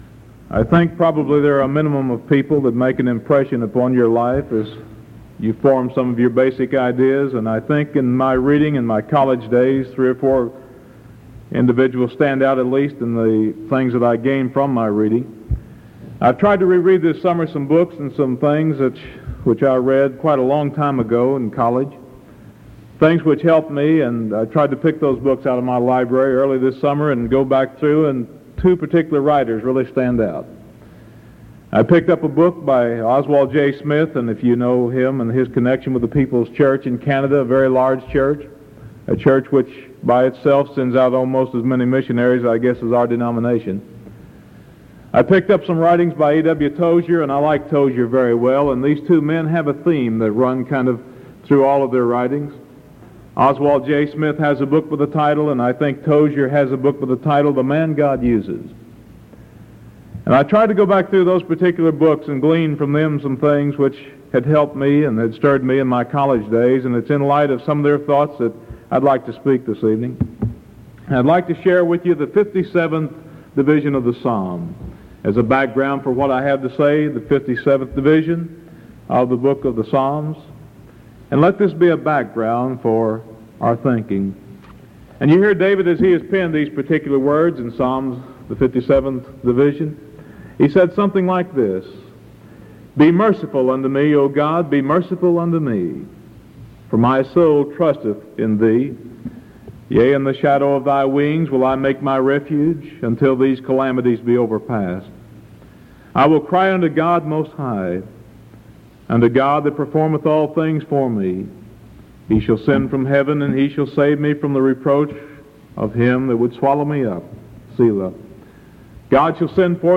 Sermon July 22nd 1973 PM